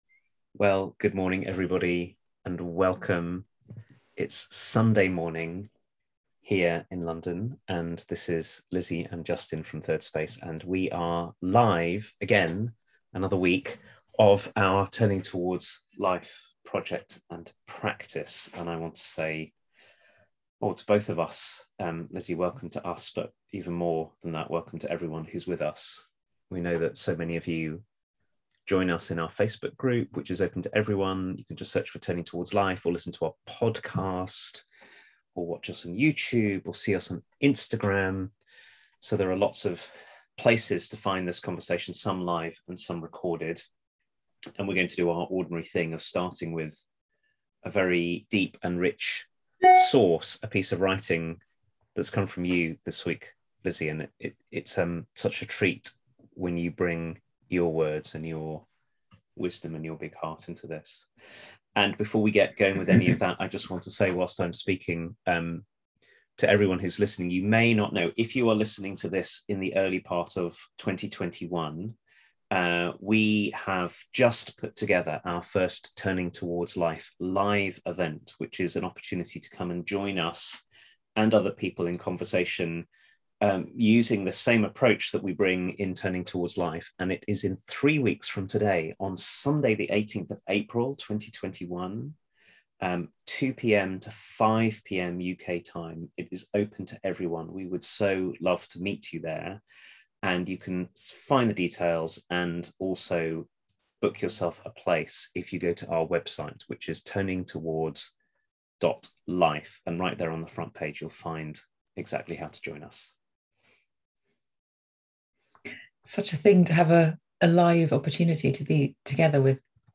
So how can we find a way to stay open, ready, and receptive in the midst of it all? This week's Turning Towards Life is a conversation about what it might take to show up for our lives in all their beauty and all their losses